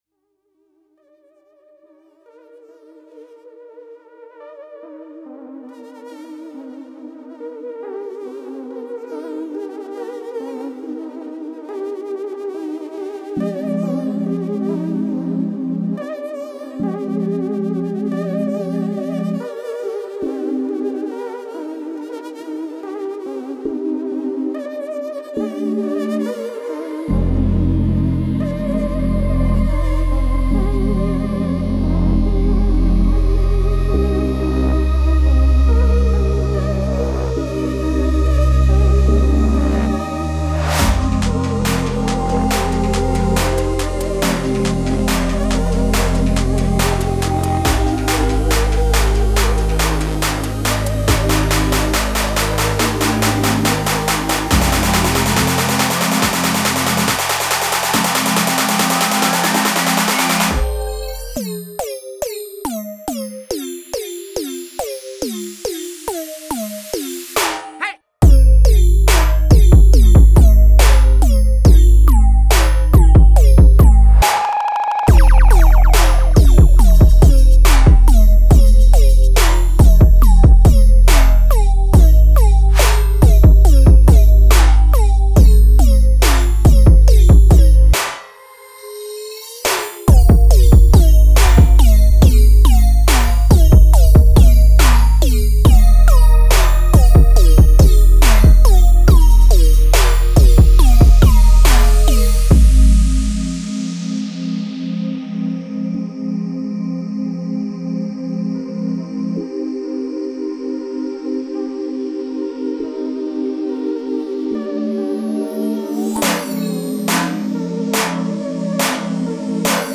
Dive into a matrix of rhythms charged with bass.